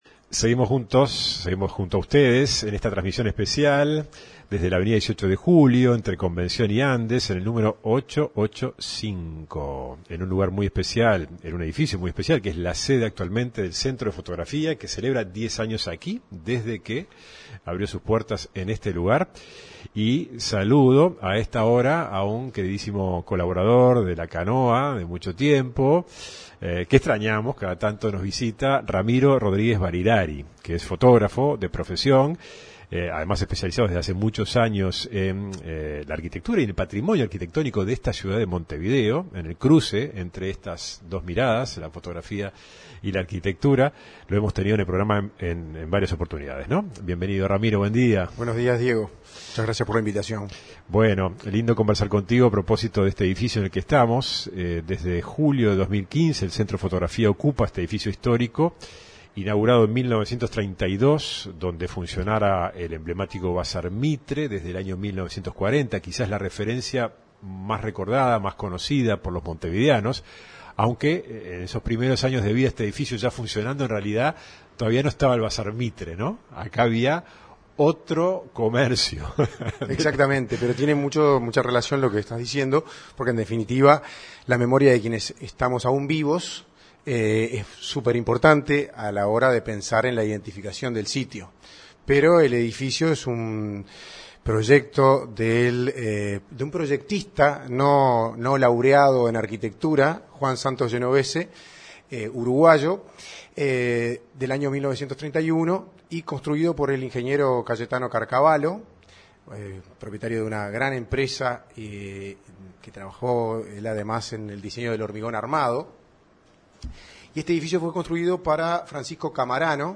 En el marco de la transmisión especial de Radio Cultura desde la sede del Centro de Fotografía, el programa La Canoa repasó la historia de un edificio que albergó primero una sastrería y luego el histórico Bazar Mitre, antes de llegar a ser sede del CdF. Sus responsables se plantearon el desafío de transformarlo en un edificio sustentable.